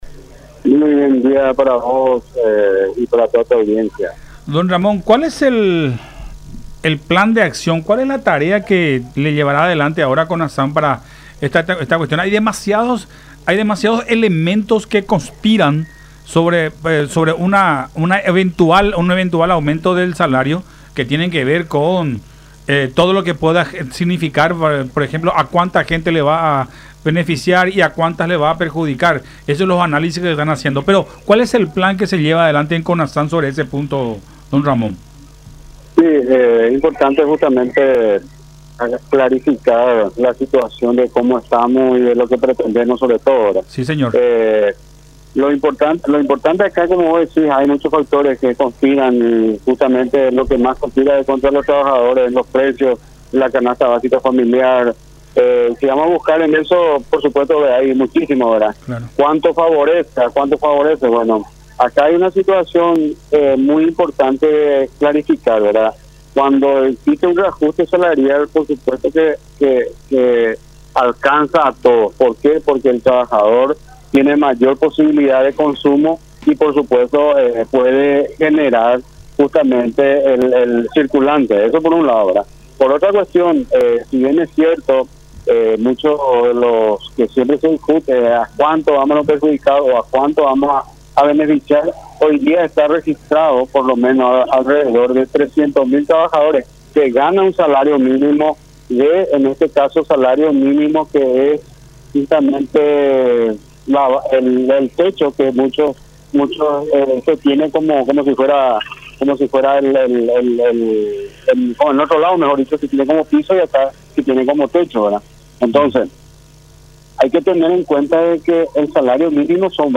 en conversación con Todas Las Voces a través de La Unión.